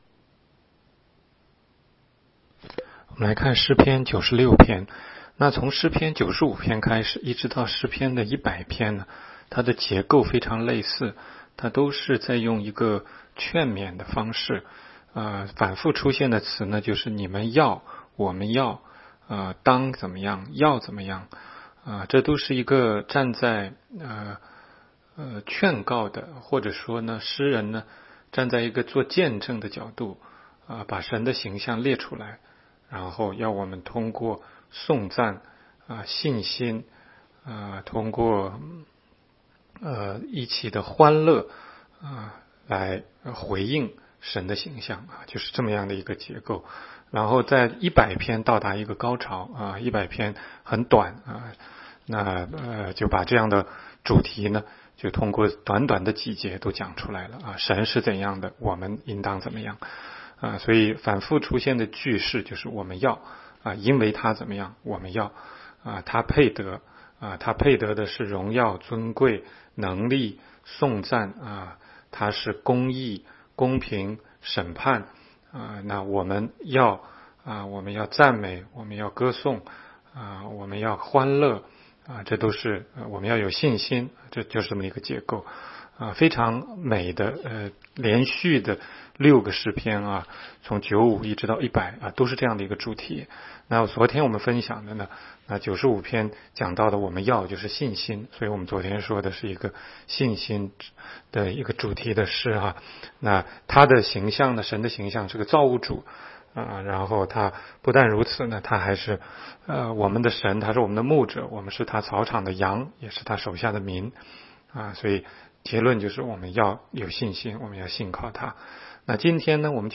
16街讲道录音 - 每日读经-《诗篇》96章